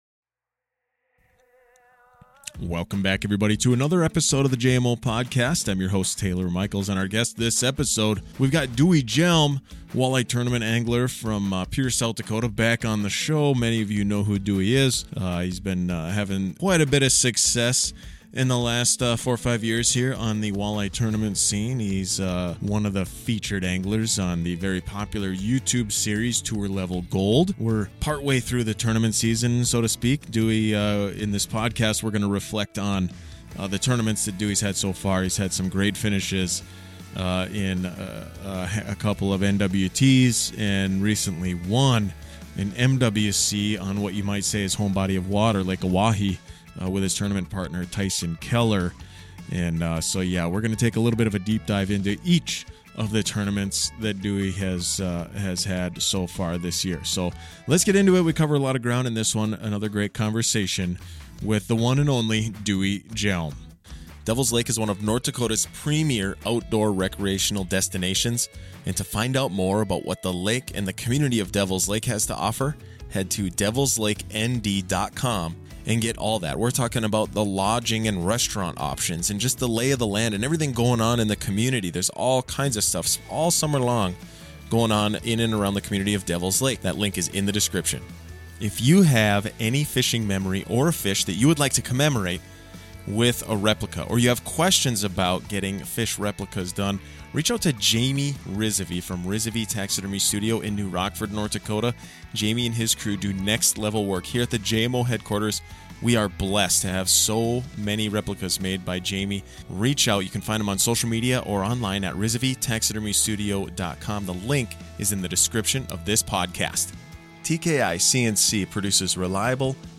In this interview we get some great information on how he has found success so far this season.